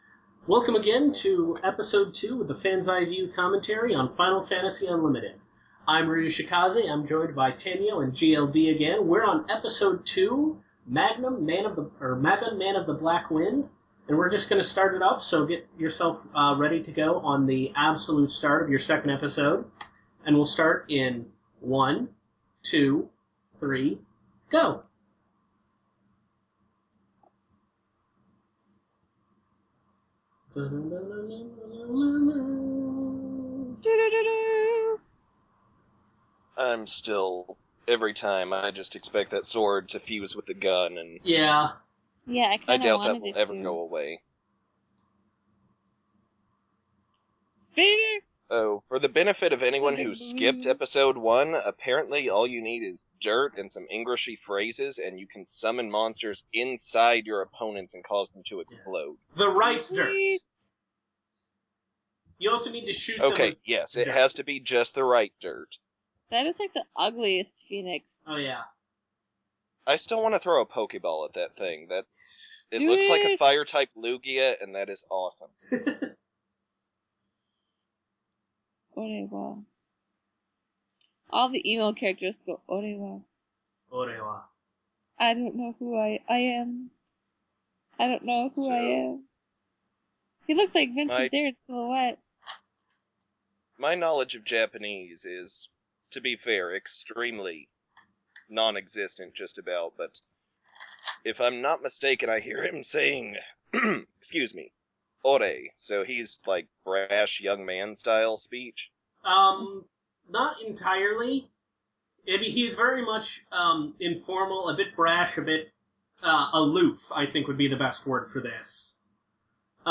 I told you we were going to do commentary for Final Fantasy Unlimited.